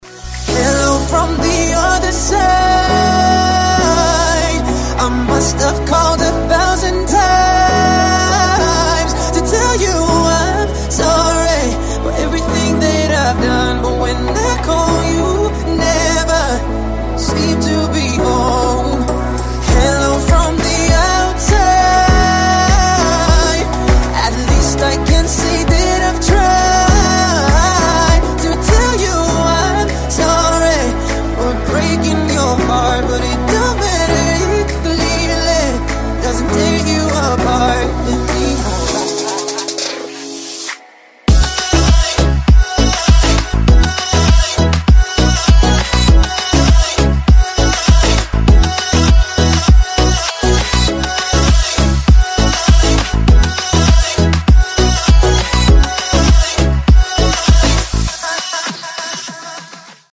• Качество: 320, Stereo
мужской вокал
громкие
remix
deep house
dance
Electronic
спокойные